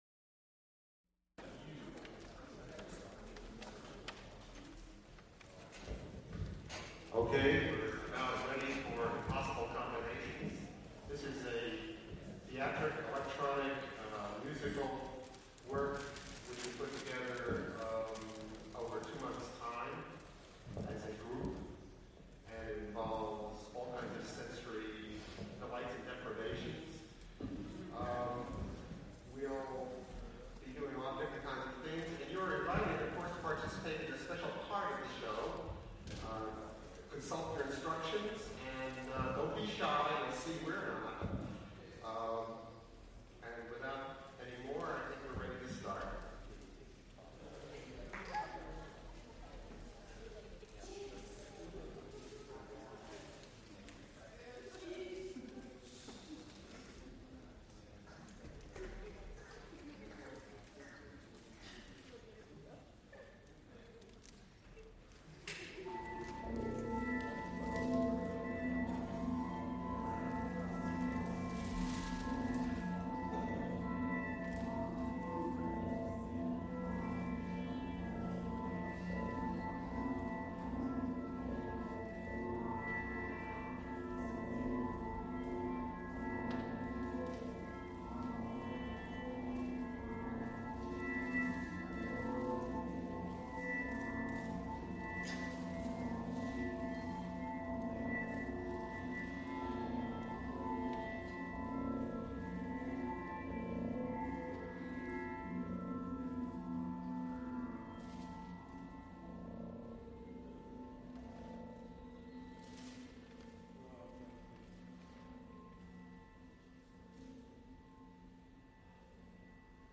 Recorded March 27, 1980, Frick Fine Arts Auditorium, University of Pittsburgh.
Impossible combinations : : Playlist Live electronic music.
Extent 1 audiotape reels : half track, 7 1/2 ips ; 12 in.